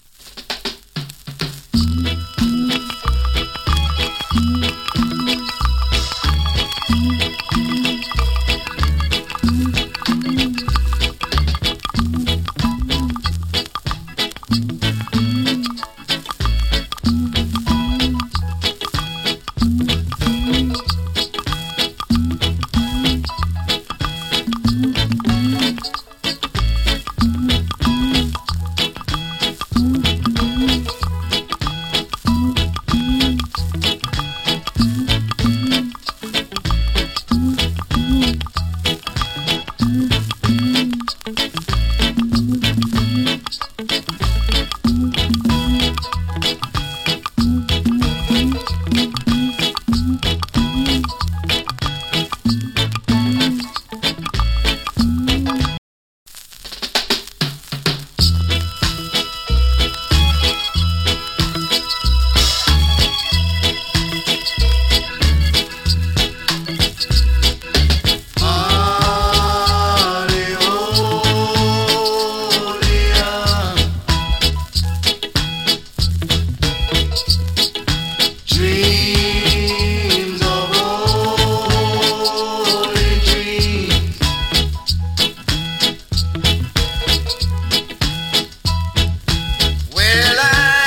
A：VG(OK) / B：VG(OK) ＊スリキズ少々有り。チリ、ジリノイズ少々有り。
パーカッション CUT !! ＋ VERSION.